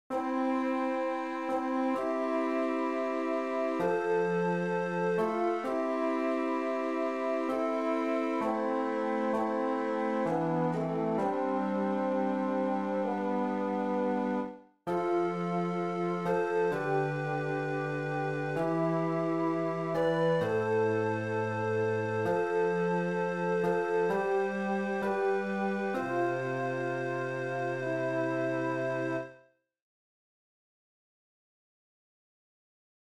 Chorproben MIDI-Files 514 midi files